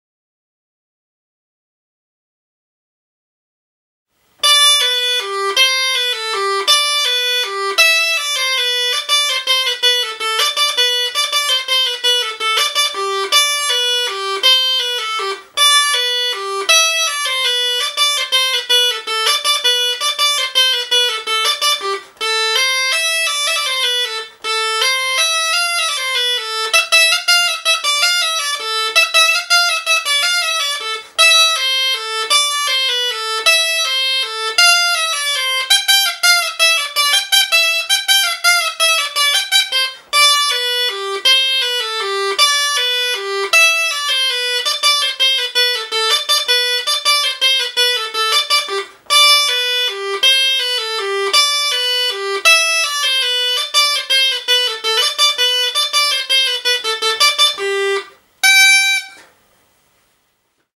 На этой странице собраны звуки жалейки — старинного русского инструмента с характерным тембром.
Звук и примеры звучания на музыкальном инструменте жалейка